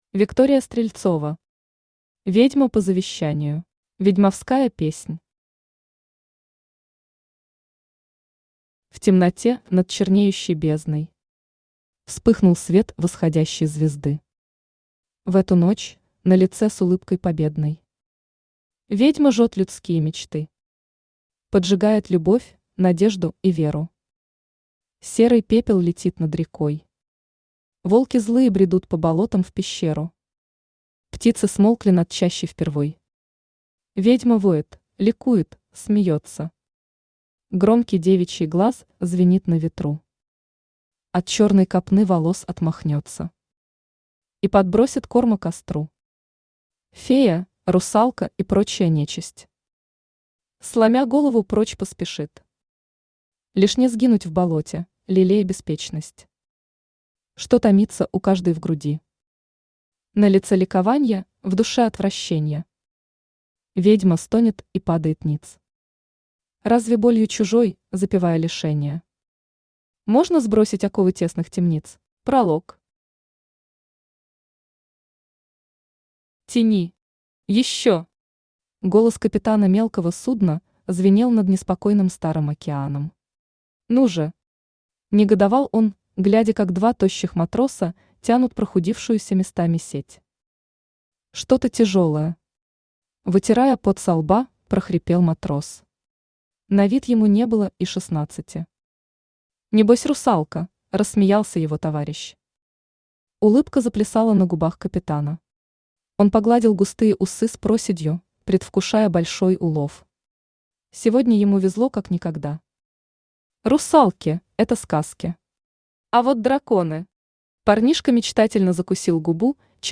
Аудиокнига Ведьма по завещанию | Библиотека аудиокниг
Aудиокнига Ведьма по завещанию Автор Виктория Стрельцова Читает аудиокнигу Авточтец ЛитРес.